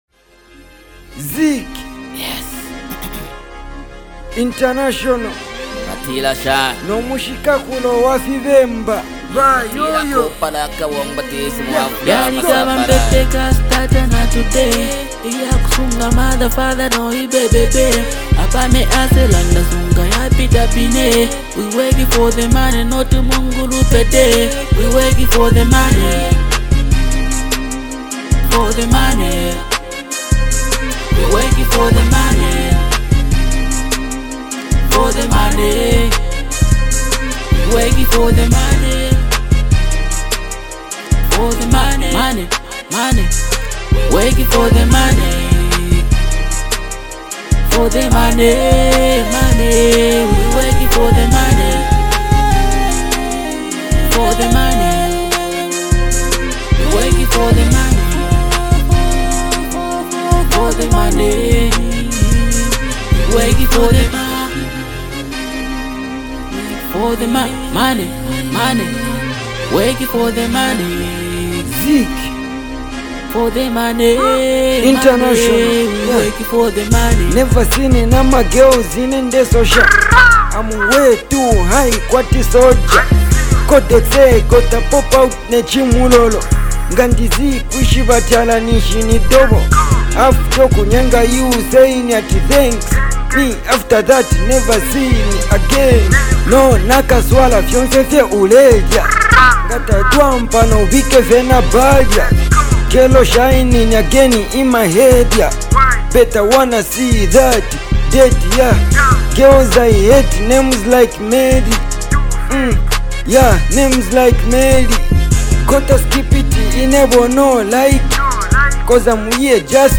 single